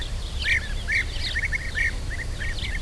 Mentre cacciano e quando volano da un posatoio all'altro emettono il loro caratteristico richiamo (120 KB).
gruccione.wav